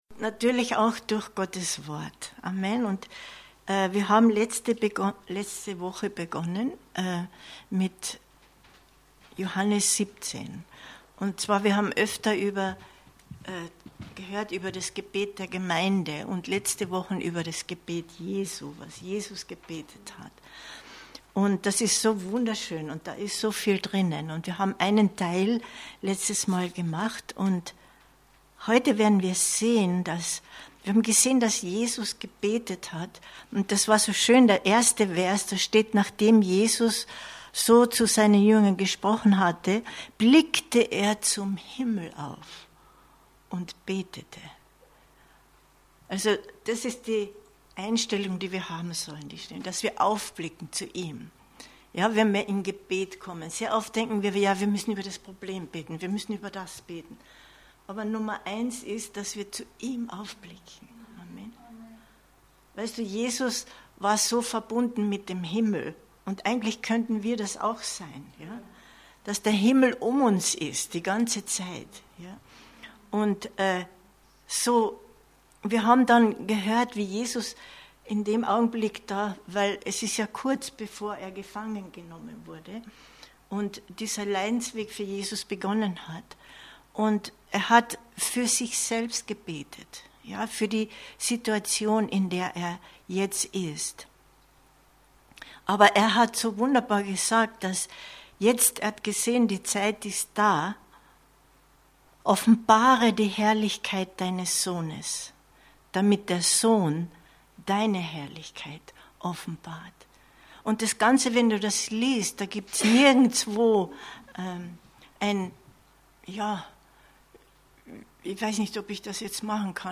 Aufnahme des Bibelabends vom Mittwoch, 24.08.2022